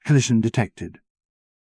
collision-detected.wav